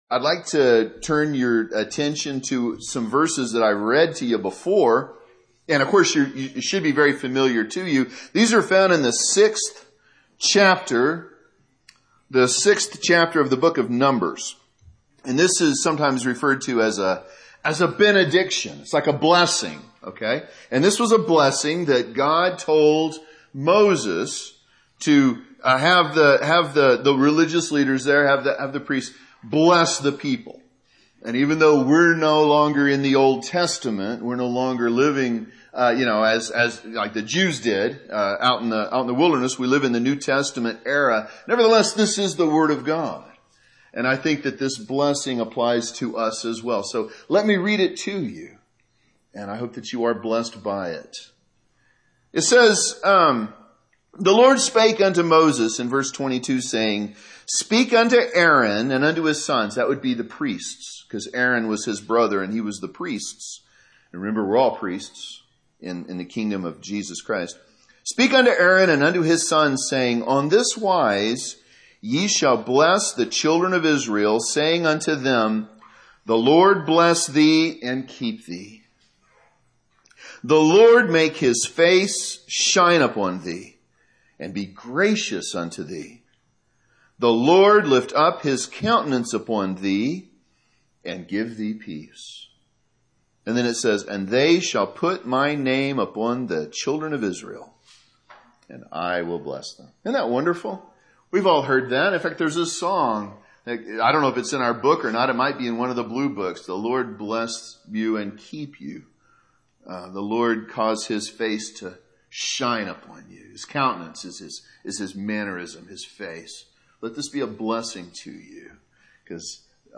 Sermons preached in other churches • Page 12
This sermon was recorded at Oxford Primitive Baptist Church Located in Oxford,Kansas